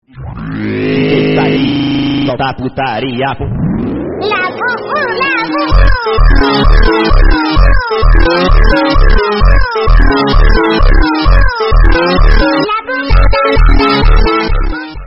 Funny Labubu Phonk